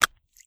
STEPS Pudle, Walk 20.wav